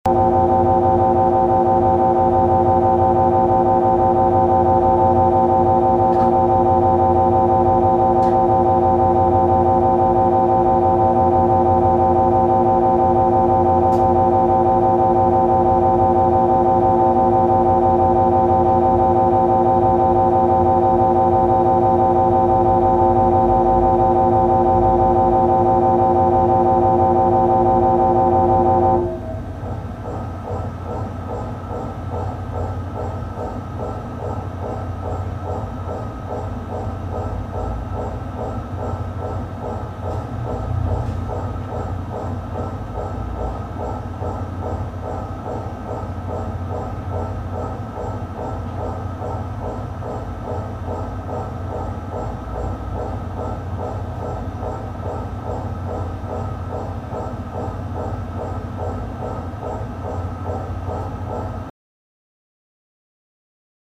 MRI; Scan In Progress, Various Knocks, Low Beeps, Machine Drone In Background